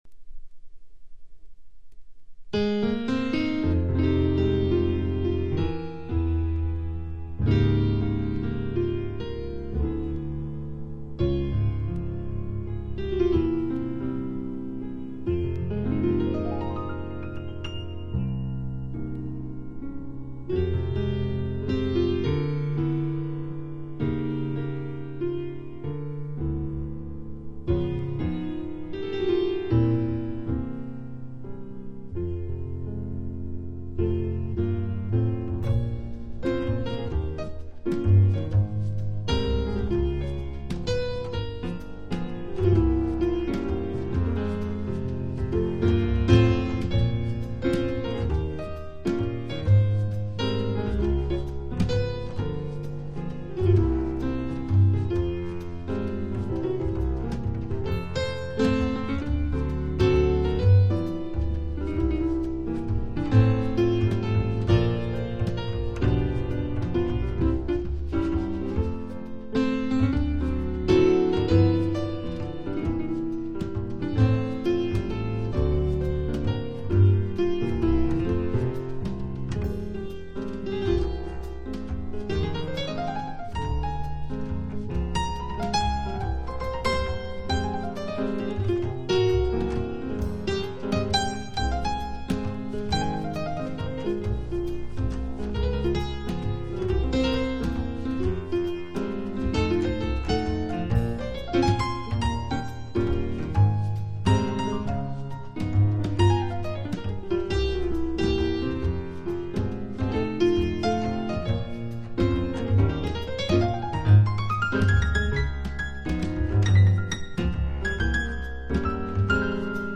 ピアニスト